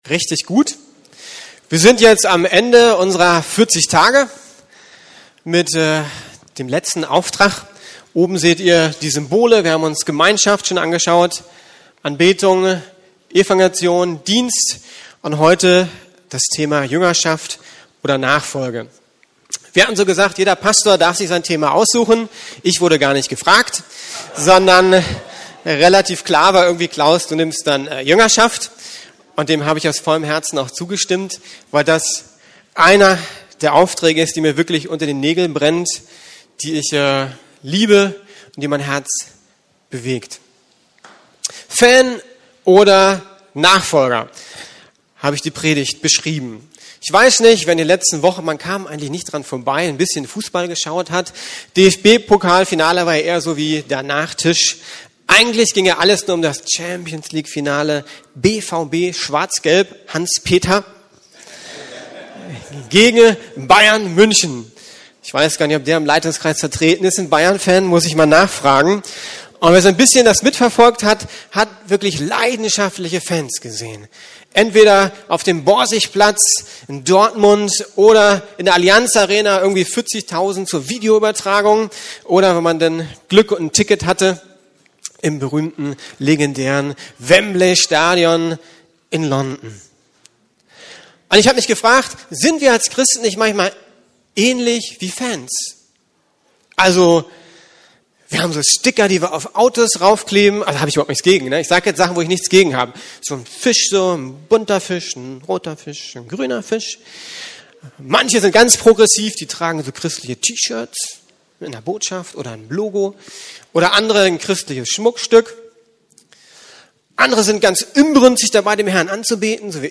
Unsere Berufung neu entdecken: Jüngerschaft ~ Predigten der LUKAS GEMEINDE Podcast